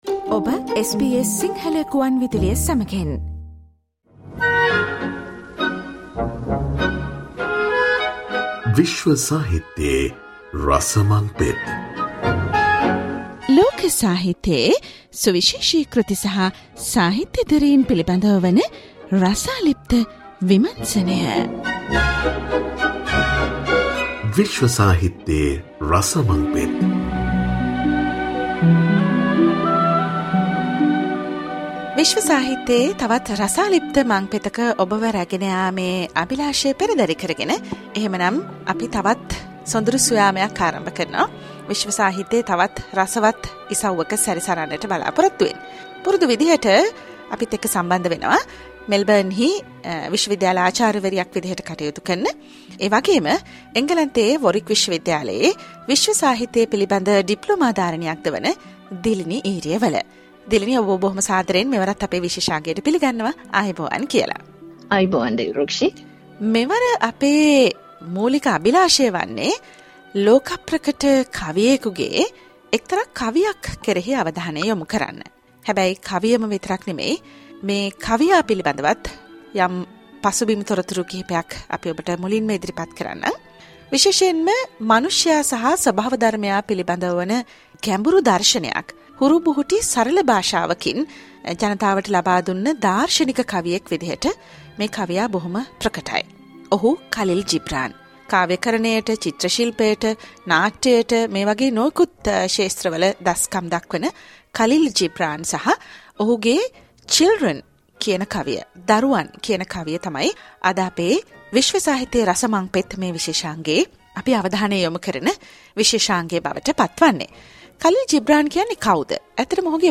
SBS Sinhala radio focused on the American-Lebonan poet and author Khalil Jibran's world-famous poem, "On Children" in this month’s World Literary discussion.